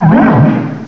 cry_not_throh.aif